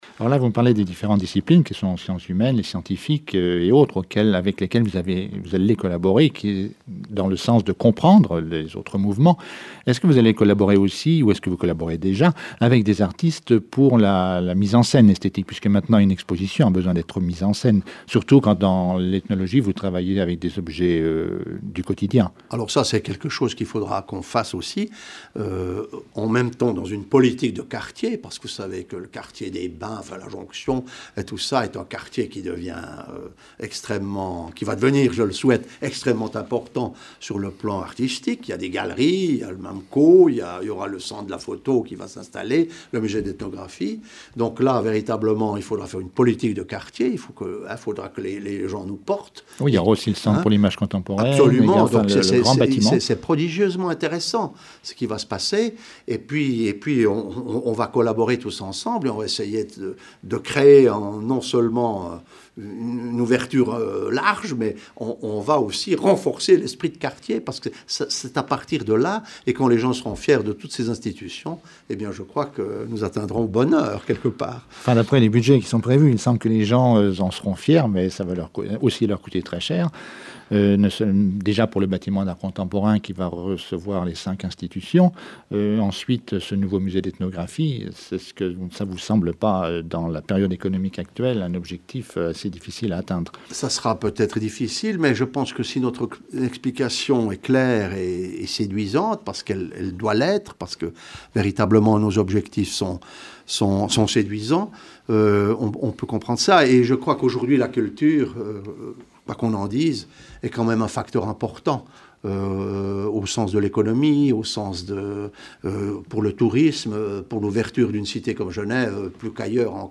Emission Cité Culture. Radio Cité.